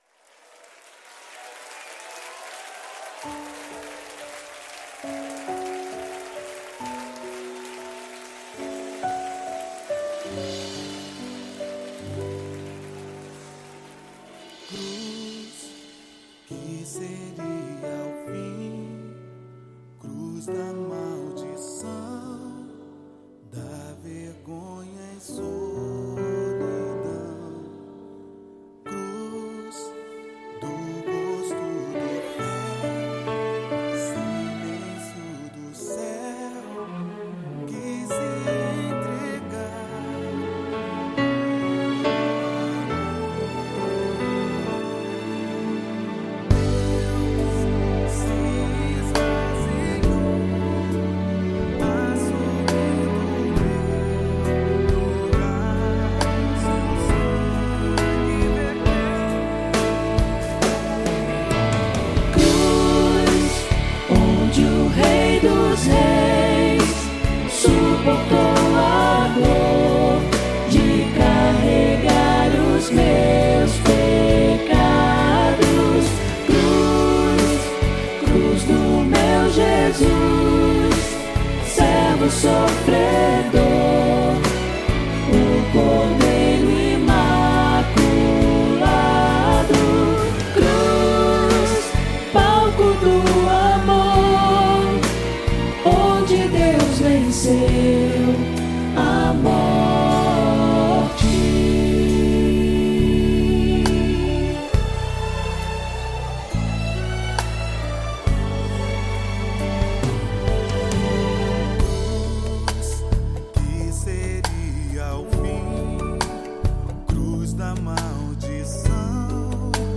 Áudio Demonstração
Arranjo Coral (SCTB)
Tonalidade: Ré maior